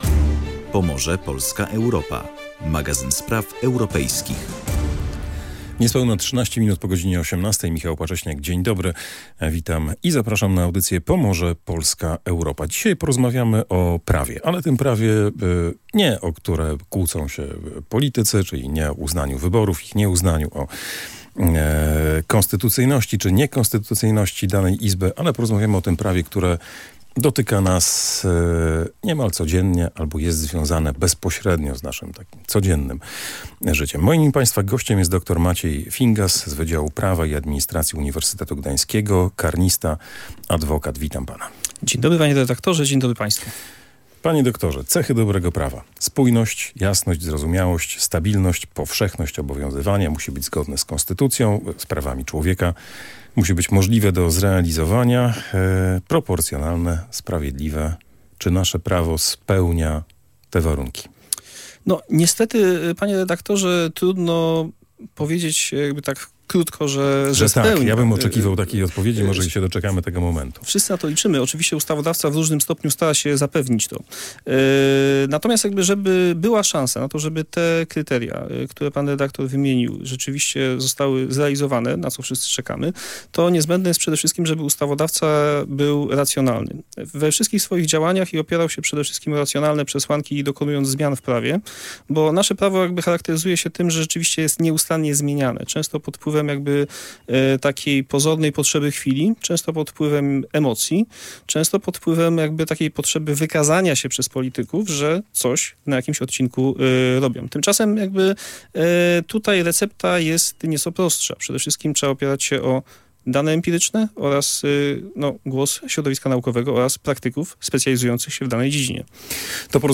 W rozmowie omówiono, dlaczego polskie prawo, mimo ciągłych nowelizacji, często jest niespójne i niejasne.